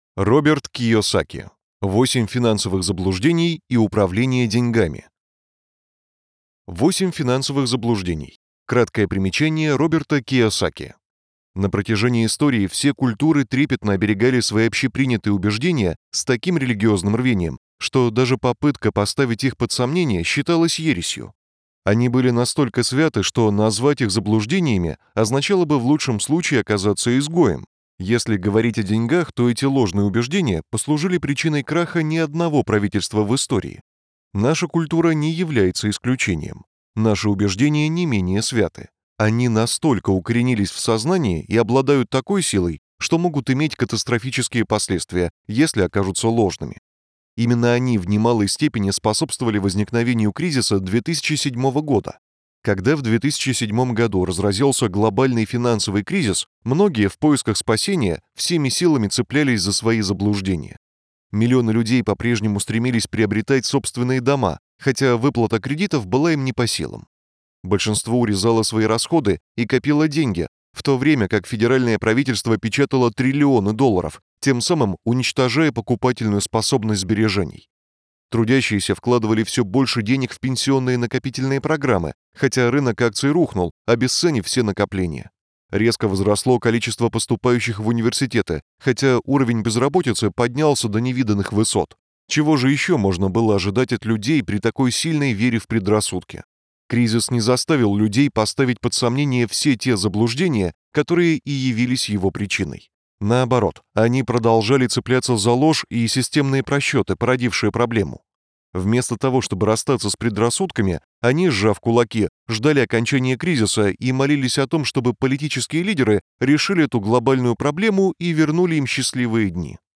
Аудиокнига 8 финансовых заблуждений. Управление деньгами | Библиотека аудиокниг